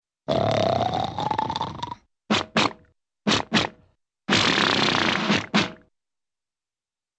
Drummer Snoring